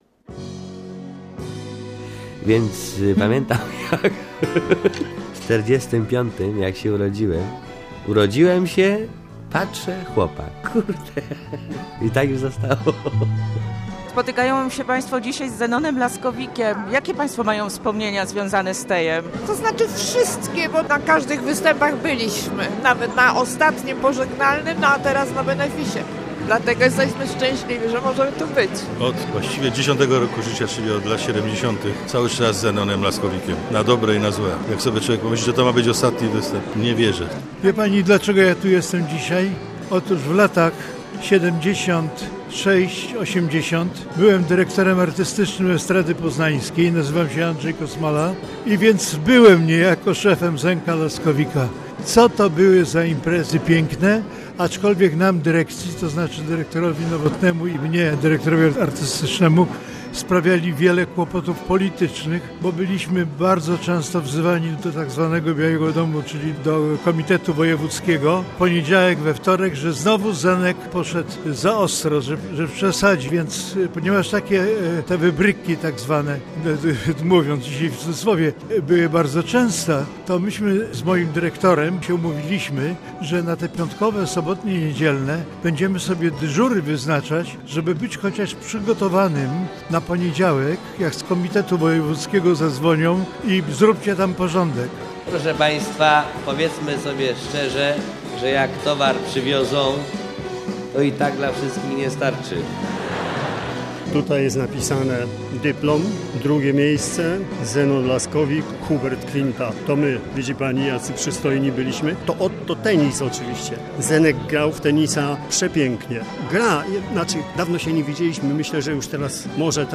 Z inteligencją i dystansem. 27 października w Teatrze Wielkim w Poznaniu odbył się jego benefis - wyjątkowy, bo będący jednocześnie pożegnaniem ze sceną. Publiczność i artyści występujący tego wieczoru stworzyli niezwykłe spotkanie - pełne wzruszeń, wspomnień i wdzięczności dla człowieka, który stał się ikoną polskiego kabaretu.